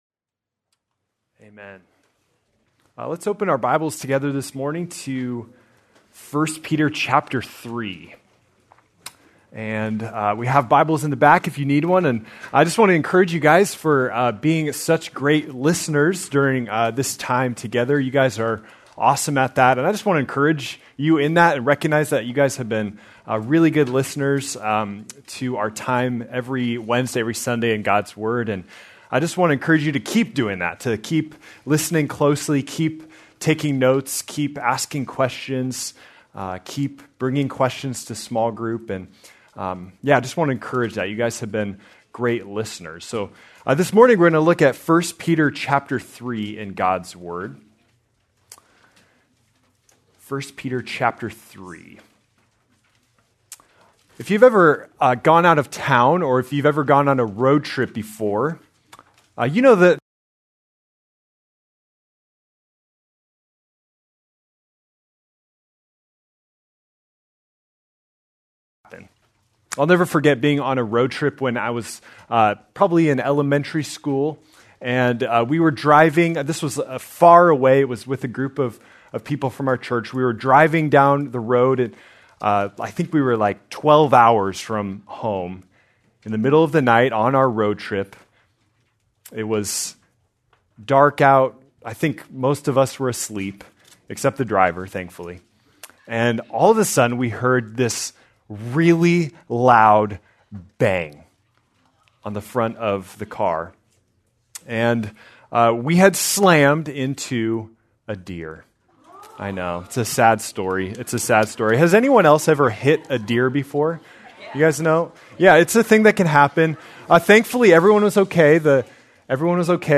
January 25, 2026 - Sermon
Please note, due to technical difficulties, this recording skips brief portions of audio.